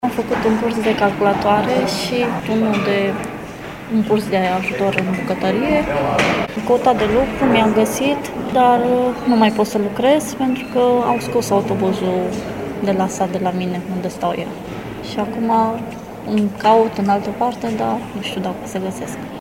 vox-joburi-persoane-vulnerabile-1.mp3